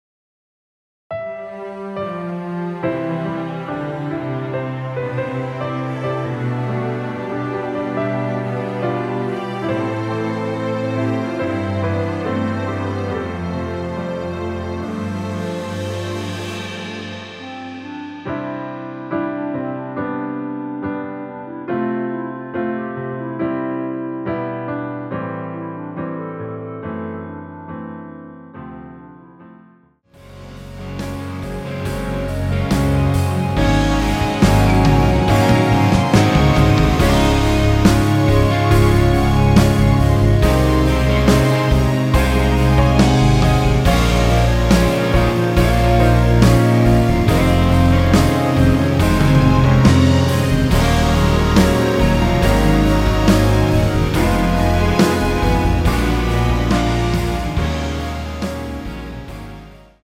멜로디 포함된(-3)내린 MR 입니다.(미리듣기 참조)
앞부분30초, 뒷부분30초씩 편집해서 올려 드리고 있습니다.
중간에 음이 끈어지고 다시 나오는 이유는